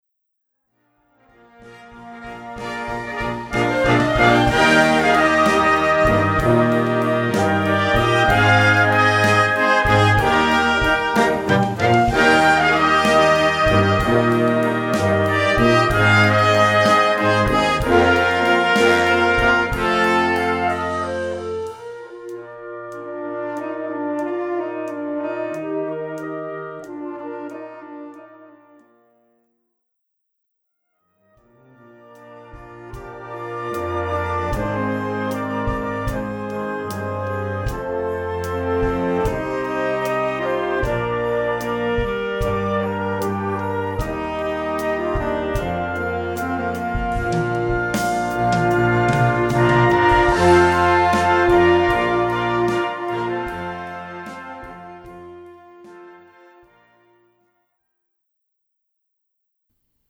Gattung: Modernes Potpourri
Besetzung: Blasorchester